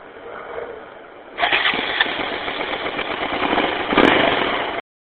Petit quizz: A quelle moto appartiennent ces bruits?
moto_2.mp3